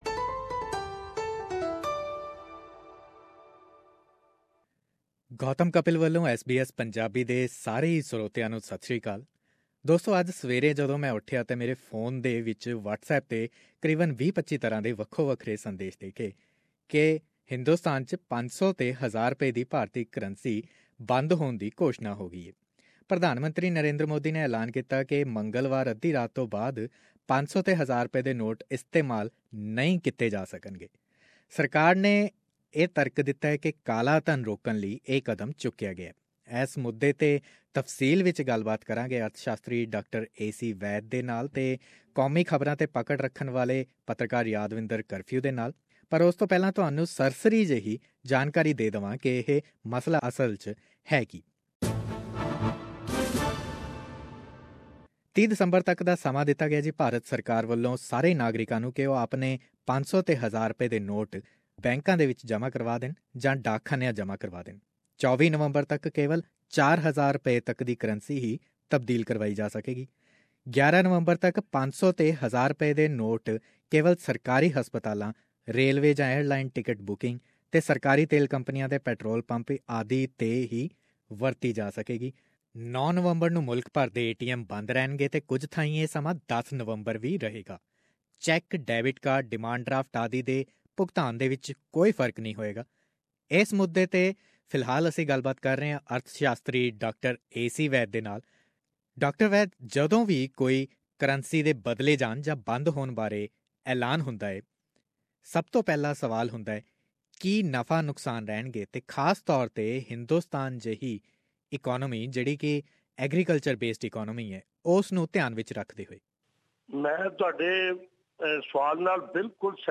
Here are extracts from conversations